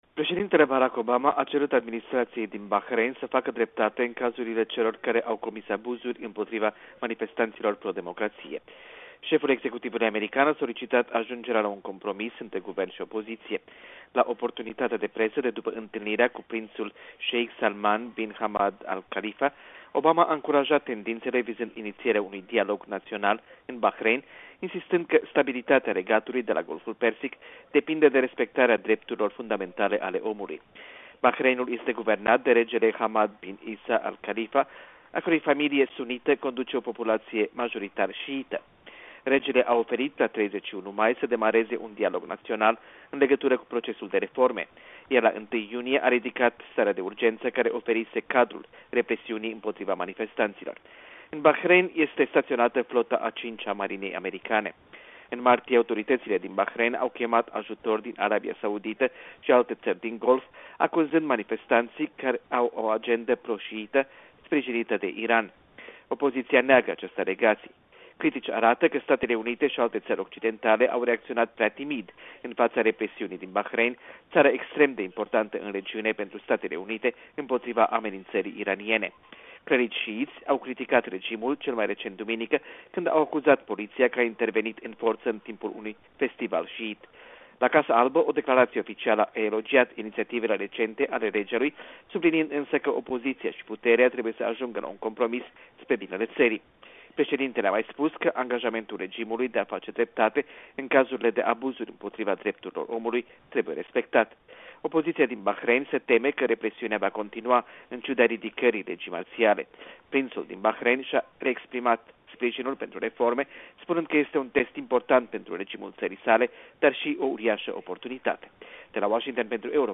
Corespondenţa zilei de la Washington